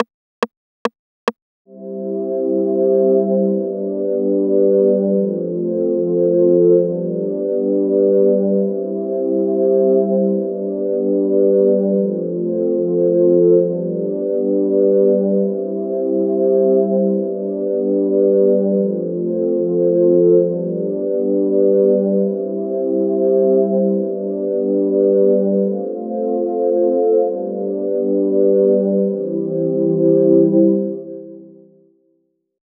String (warm).wav